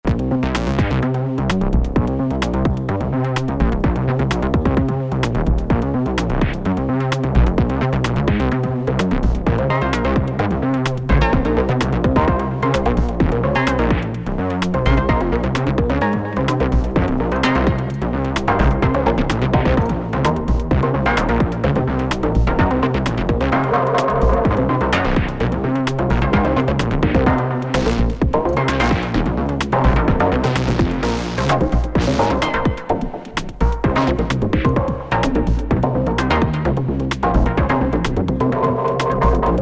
sounds like when you have two sequencers cross-talking and locked to the same root notes and/or rhythm.
This little experiment involves a DB-01 and SH-01a, which are both midi and CV capable.
The DB is playing a 32 step sequence, but it’s all restricted to the same note: C1 – not so compelling until you start sending pitch CV into it from another machine, in this case the SH.
The SH-01a is playing a pretty arbitrary set of notes + chords (notably) + rests, entered with the onboard step sequencer.
In some parts of the little recording below, the SH is locked to the DB-01s trigger timings; in other parts, it’s not. Pretty easy to hear in the L and R channels. The DB is always locked to the current (or most recently played) root note of the SH.
I like how the two voices sound interlinked but still their own beast.